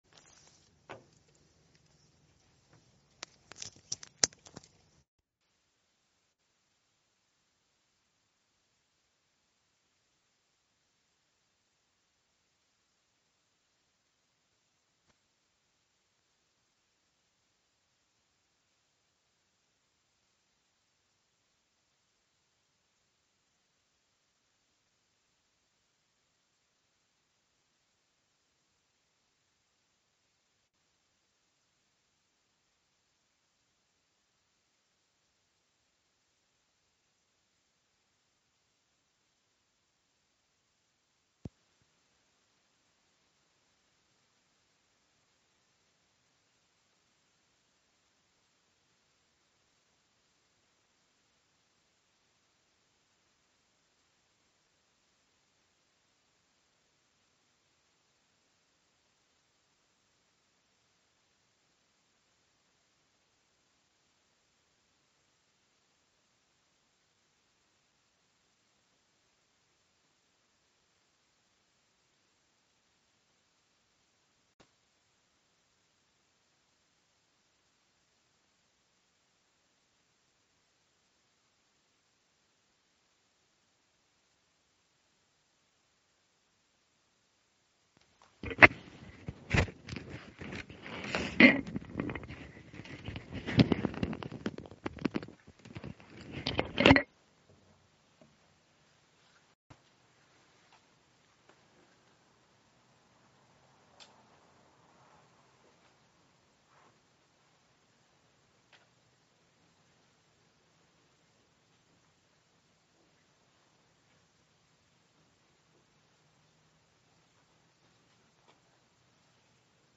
الموقع الرسمي لفضيلة الشيخ الدكتور سعد بن ناصر الشثرى | مسائل في تخريج الفروع على الأصول- الدرس (13)